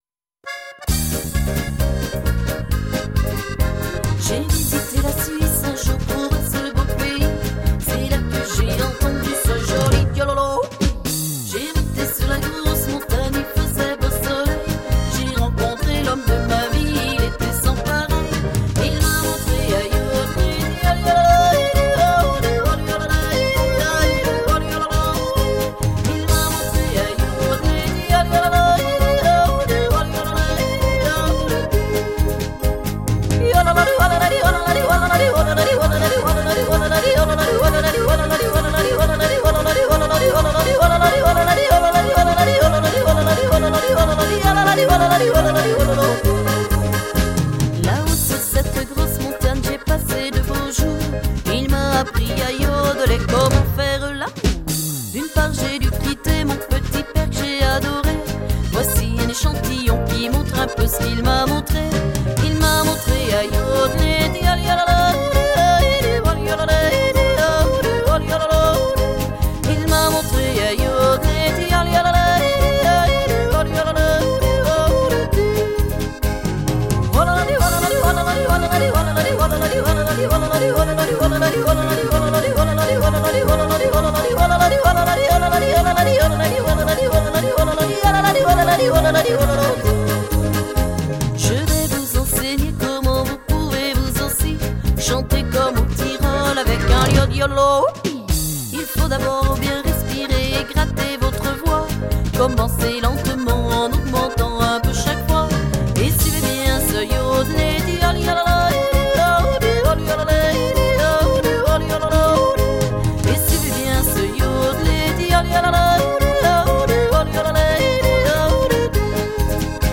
Genre: Traditionnel